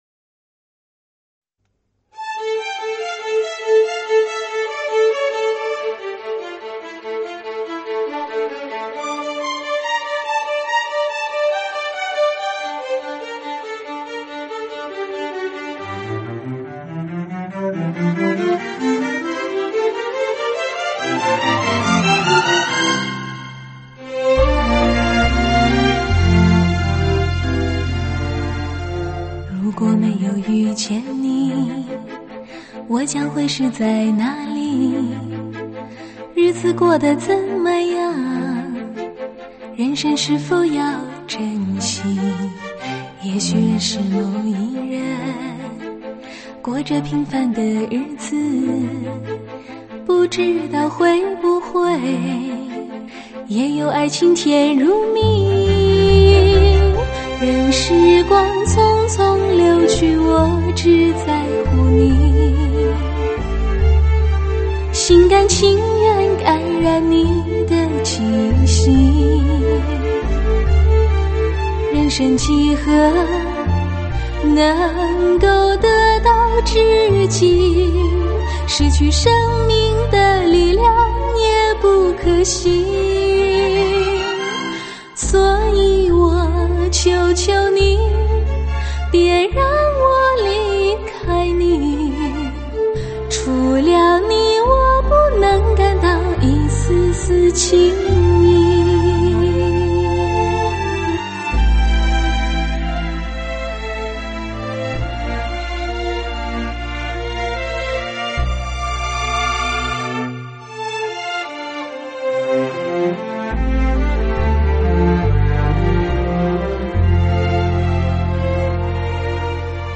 配乐上全部采用真乐器做伴奏；
弦乐华丽高贵、低音提琴颇具牛筋味；钢琴、吉他的颗粒感强，钢琴的质感录音得很好，细节也纤毫毕现。